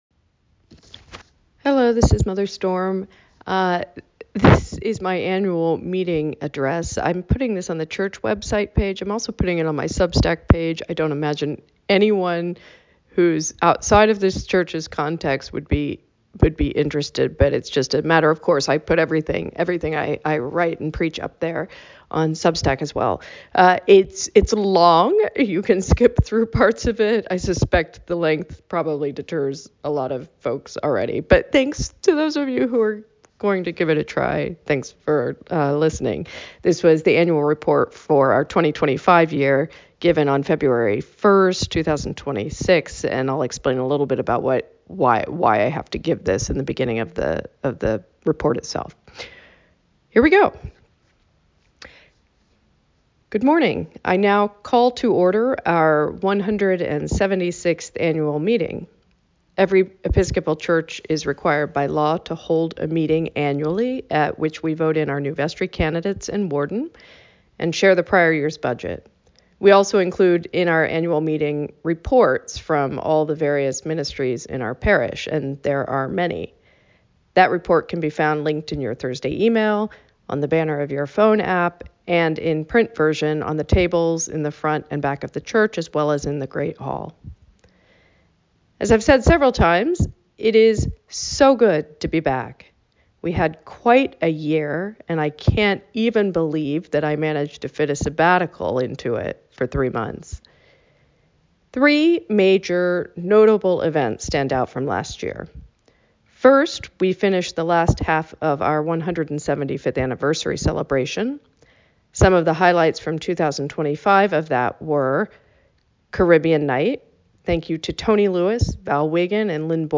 Preached Sunday morning, February 1 at the 9 & 10:30 am services